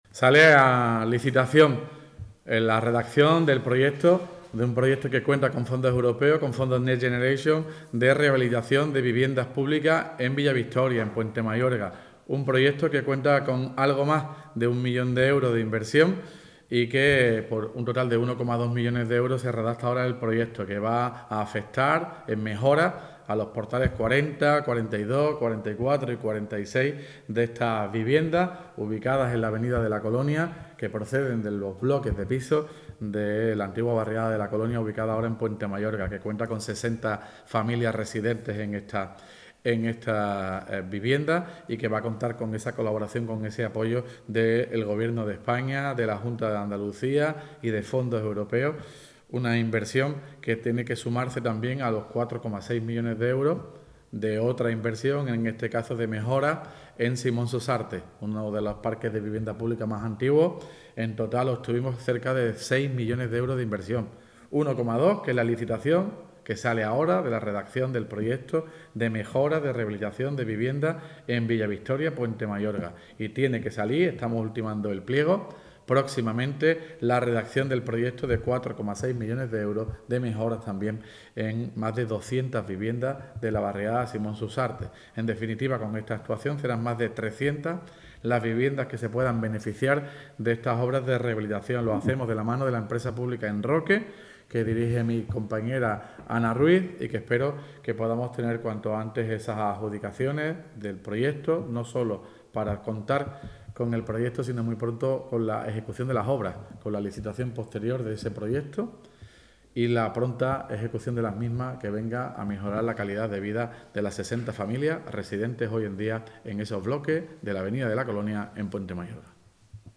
REHABILITACION VIVIENDAS VILLAVICTORIA (TOTAL ALCALDE) 9 MAYO 2025.mp3